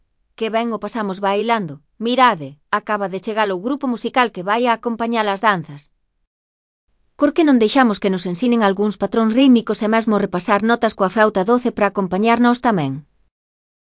Interpretación da escala de DóM descendente coa frauta.